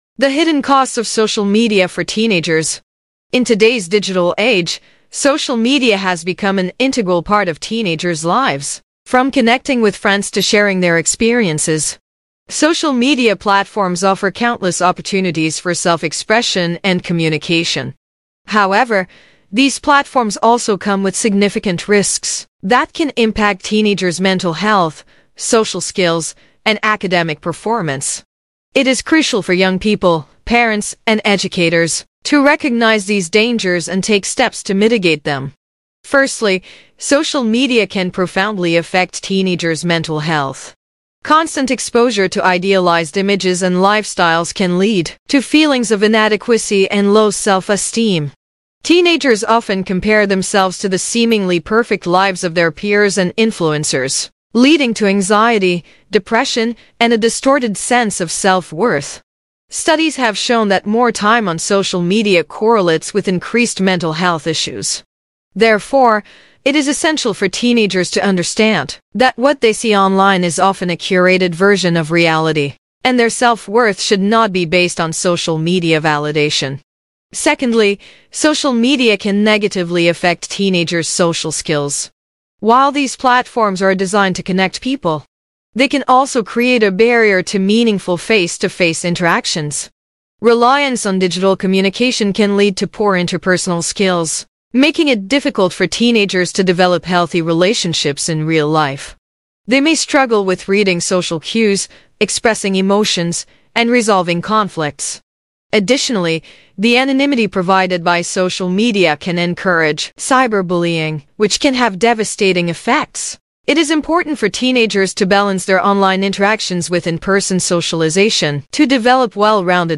Hortatory-reading-audio.mp3